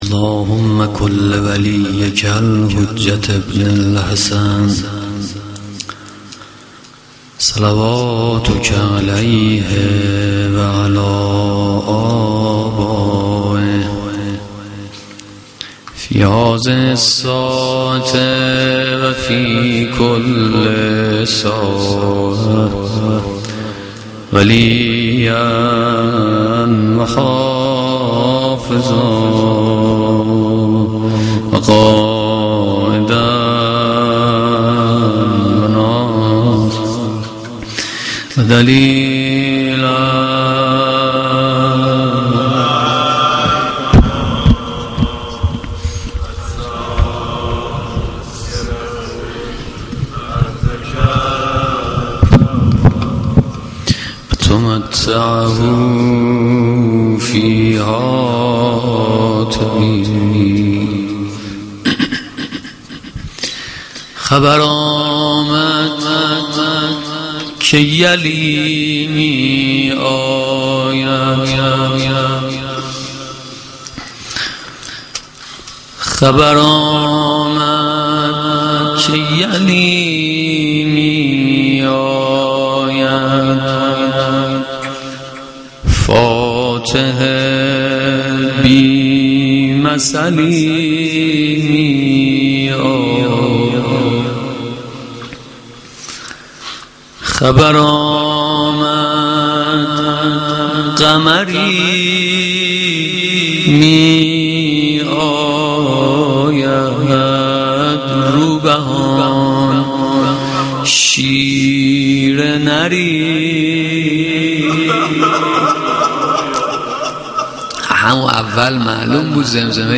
مداحی
Shab-8-Moharam-02.mp3